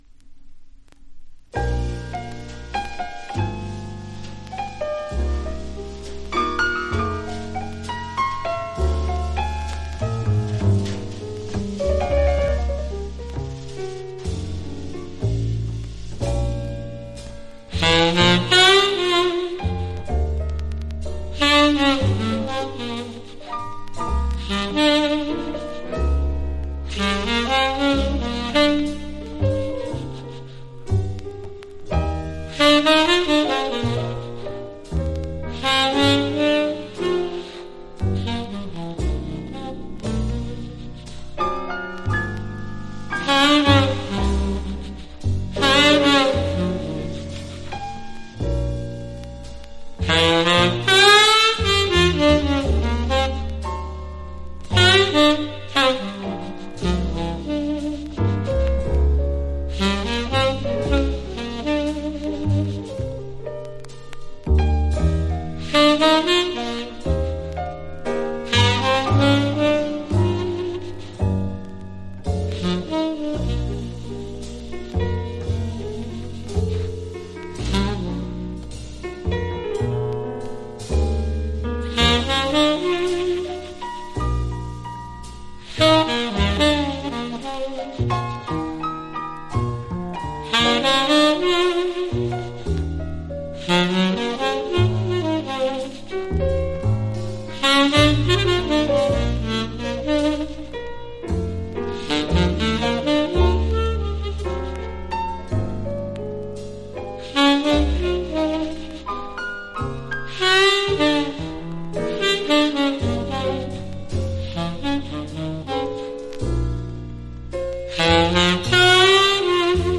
（プレスによりチリ、プチ音ある曲あり）※曲名をクリックすると試…